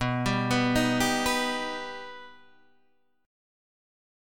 B Augmented